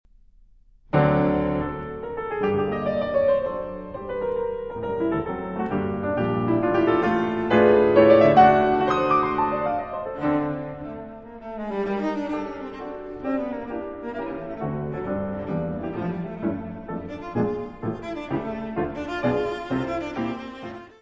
violoncelo
piano
: stereo; 12 cm
Área:  Música Clássica
Sonata for cello and piano in G minor, op.65
Finale. Allegro.